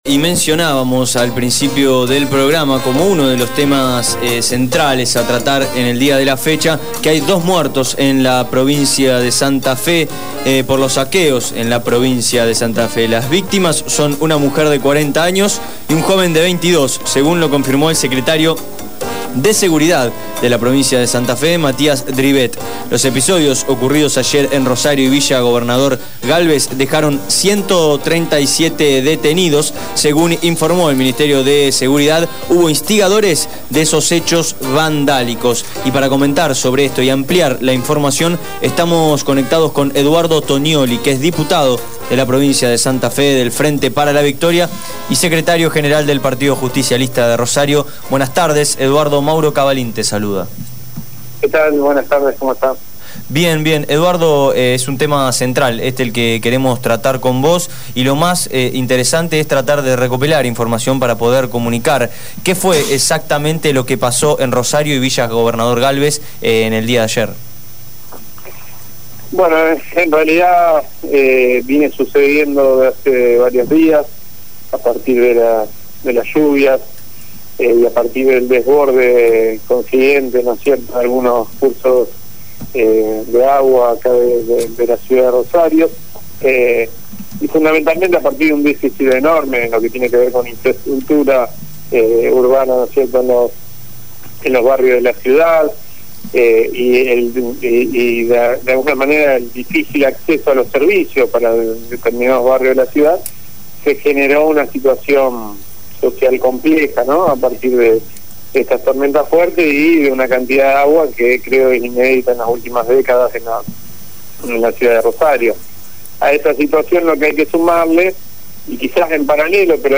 Eduardo Toniolli, diputado de la Provincia de Santa Fe y dirigente del Movimiento Evita, habló con el programa “Abramos la Boca” de Radio Gráfica.